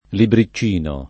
libri©©&no] s. m. — antiq. o region. libricino [libri©&no] — oggi domin. la forma -icci- analogam. a libricciattolo e libricciolo, diversam. invece dagli altri dim. in -icin- (in nessun altro caso -iccin-): botticina, conticino, c(u)oricino, fiumicino, fonticina, fraticino, lumicino, monticino, noticina, ossicino, pianticina, ponticino, posticino, punticino